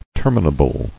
[ter·mi·na·ble]